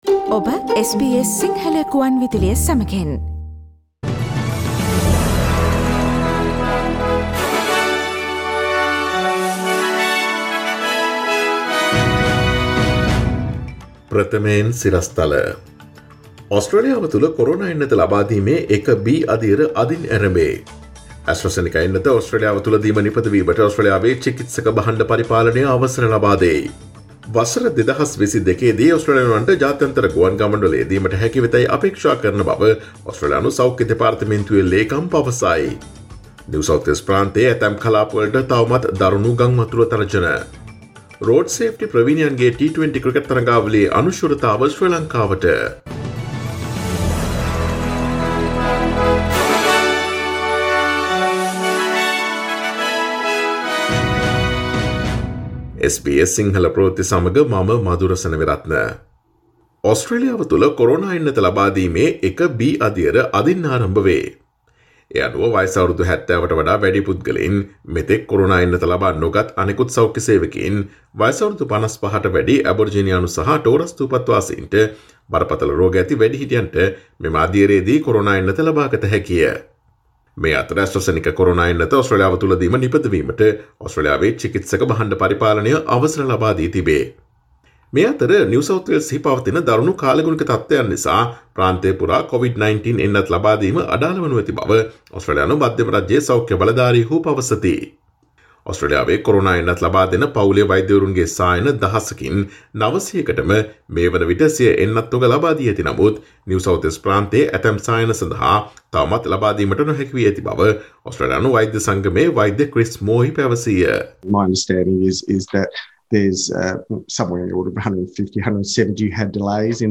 Here are the most prominent Australian, International, and Sports news highlights from SBS Sinhala radio daily news bulletin on Monday 22 March 2021.